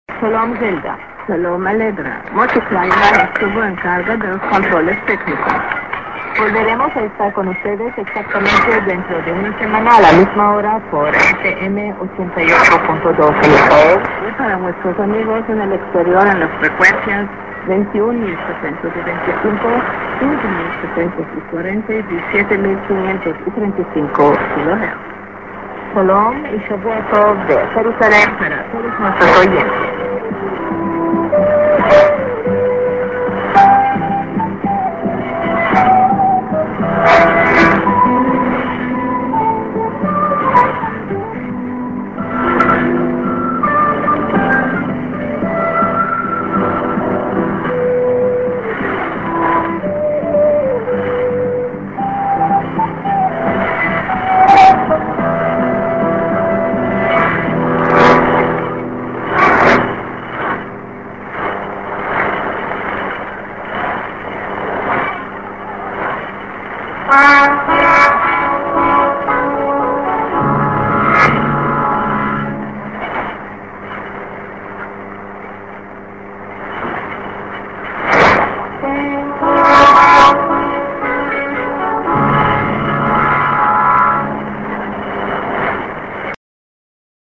End SKJ+ID(women)->30":SJ->1'07":IS